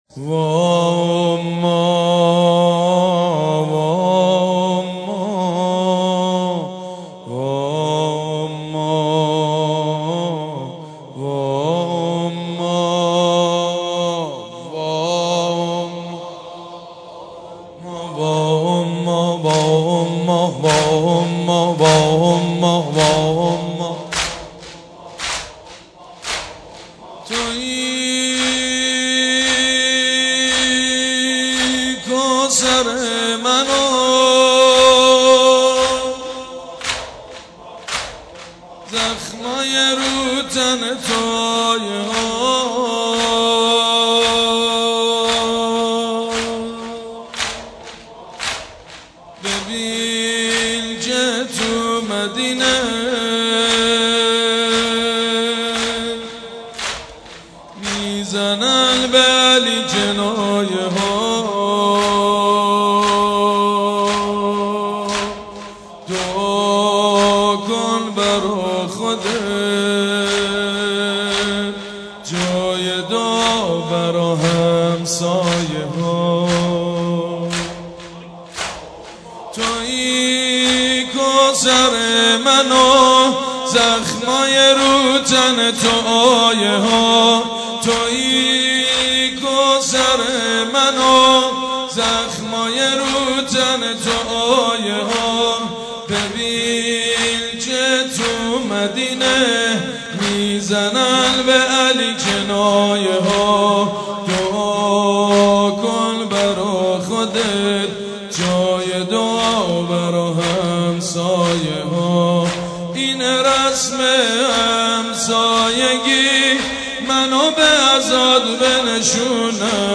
سینه زنی در شهادت ام الایمه حضرت زهرا(س